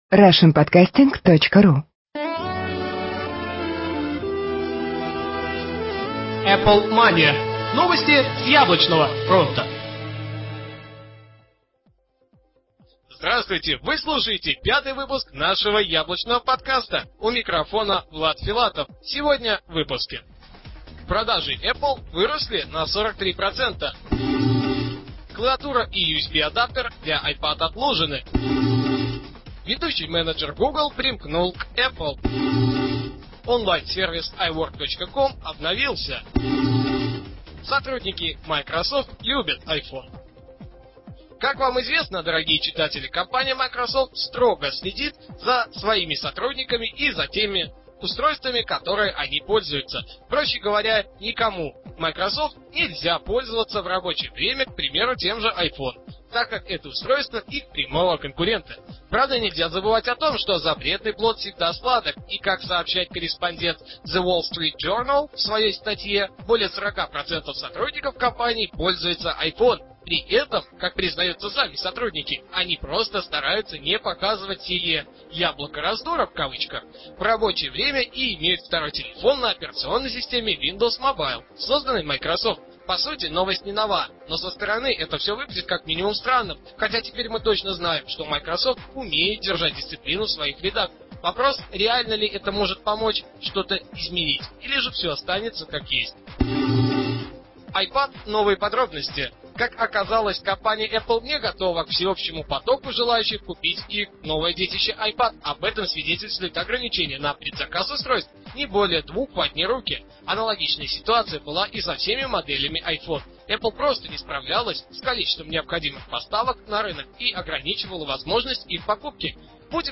"Apple Mania" - еженедельный новостной Apple подкаст
Жанр: новостной Apple-podcast